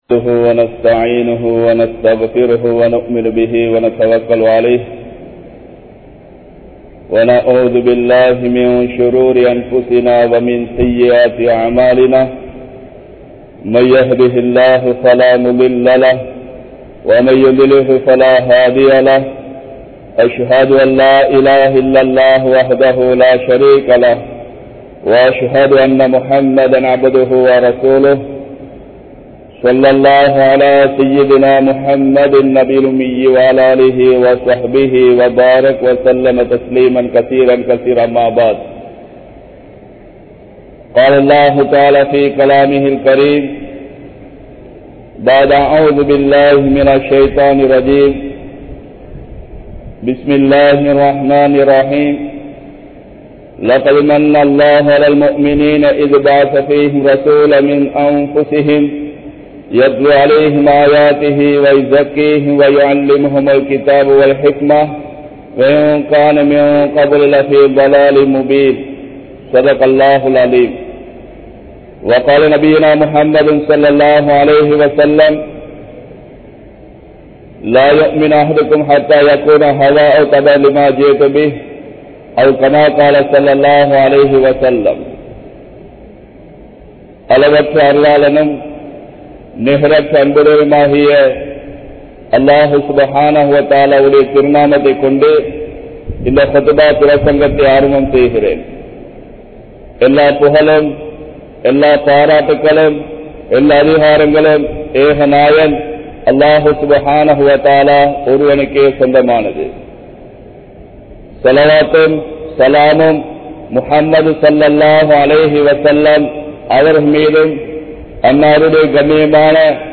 Nabiyavarhalin Valimuraien Perumathi (நபியவர்களின் வழிமுறையின் பெறுமதி) | Audio Bayans | All Ceylon Muslim Youth Community | Addalaichenai
Muhiyaddeen Grand Jumua Masjith